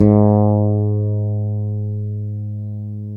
Index of /90_sSampleCDs/Roland - Rhythm Section/BS _E.Bass 3/BS _8str Fretls